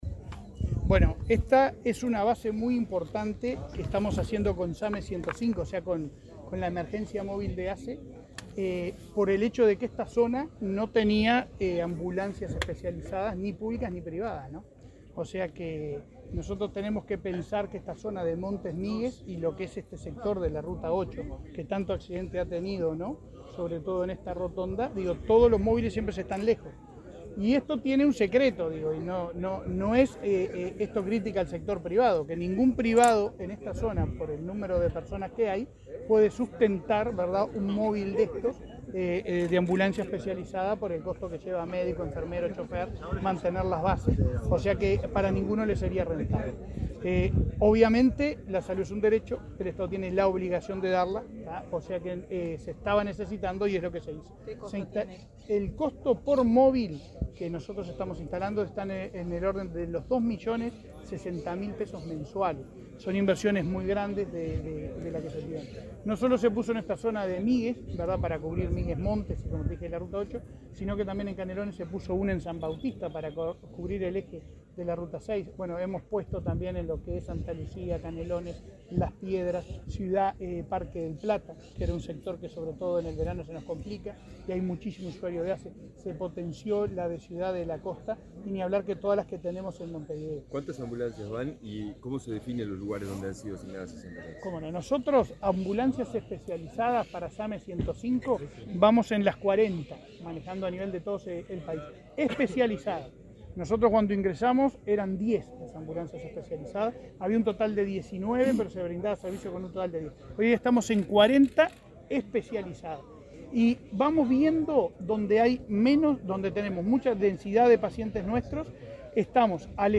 Declaraciones del presidente de ASSE, Leonardo Cipriani a la prensa
Declaraciones del presidente de ASSE, Leonardo Cipriani a la prensa 26/05/2022 Compartir Facebook X Copiar enlace WhatsApp LinkedIn El presidente de ASSE, Leonardo Cipriani, participó este jueves 26 de la inauguración de la base SAME 105 en la localidad de Migues, departamento de Canelones. Luego, dialogó con la prensa.